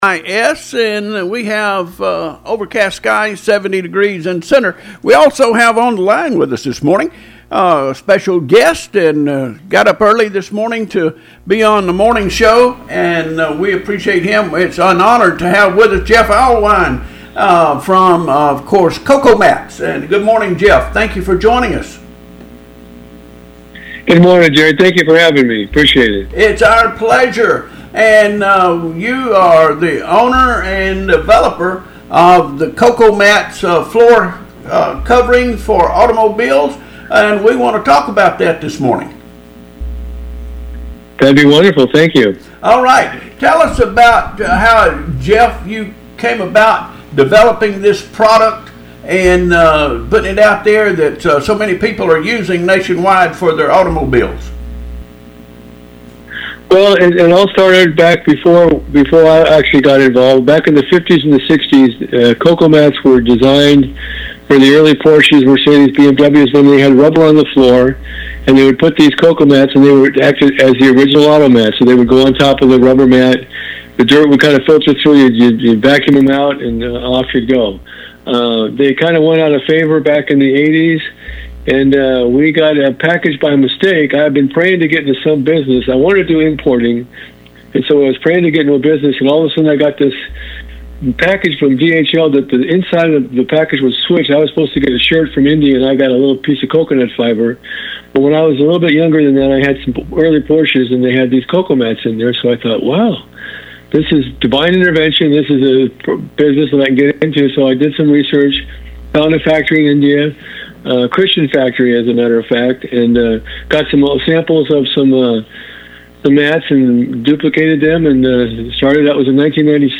Special interview